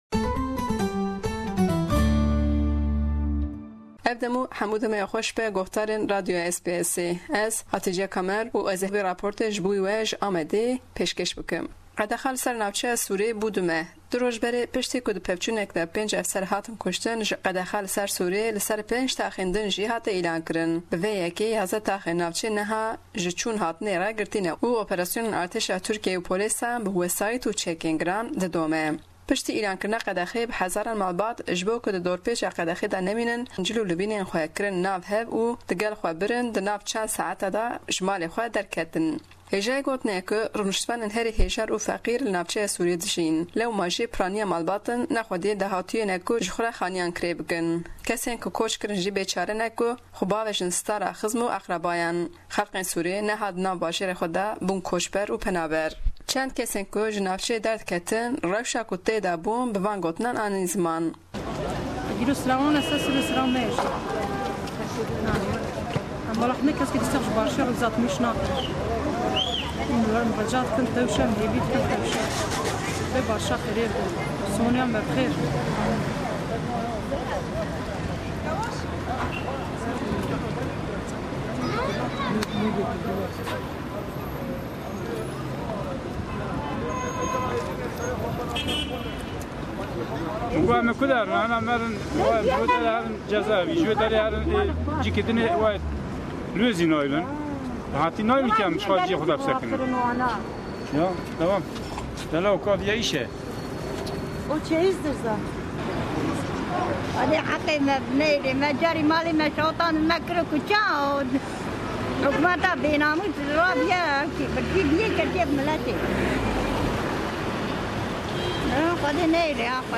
Raport li ser rewşa dawî ya qedexeyên çûnhatinê yan li herêmê ye. Qedexa li ser navçeya Sur'a Amedê ji 6 taxan zêdekirin li ser 11 taxan.